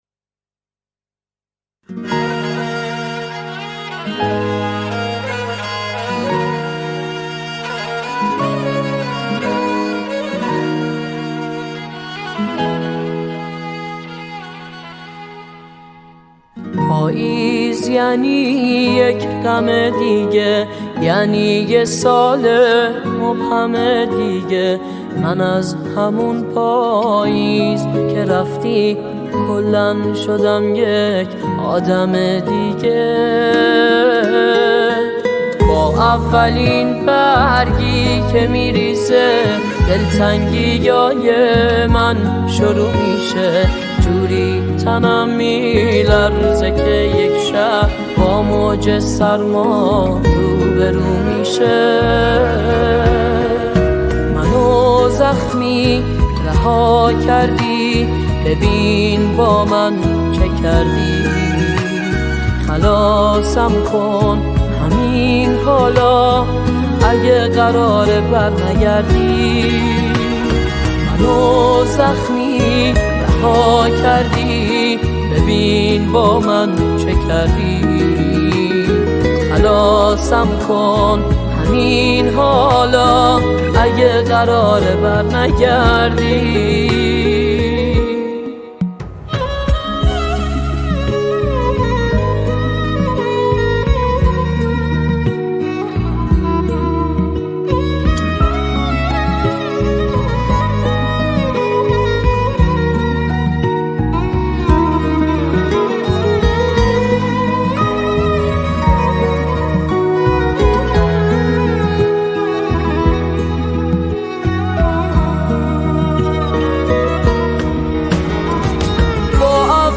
ویلن و کمانچه
گیتار